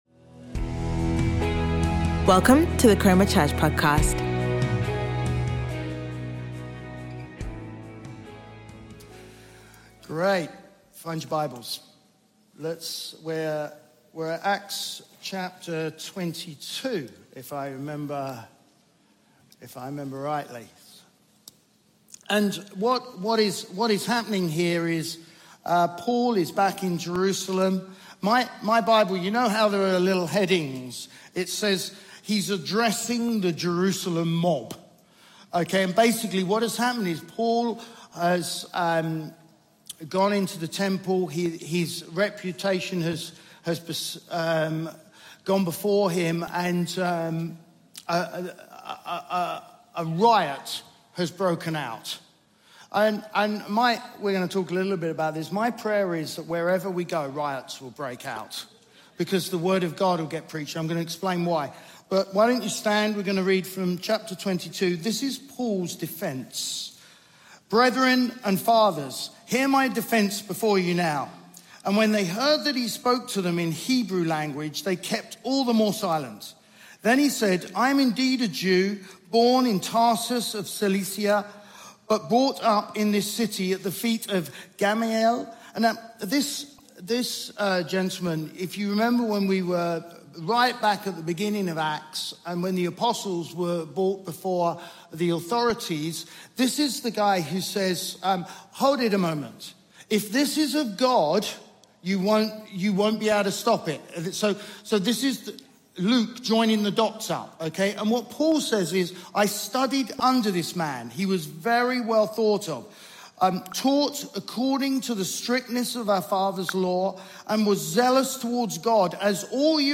Chroma Church Live Stream
Sunday Sermon